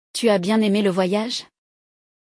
O som de “oy” em francês é semelhante a “ou-i” (como em voyage = vo-ia-j).